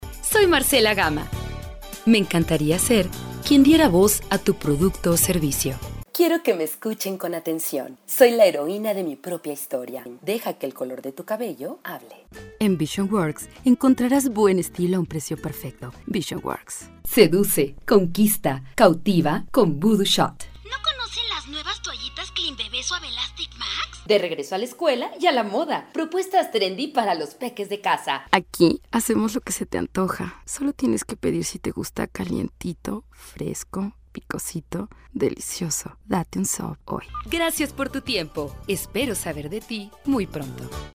Espanhol - América Latina Neutro